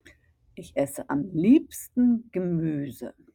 (Ich ESS-e am LIEB-sten Ge-MÜ-se)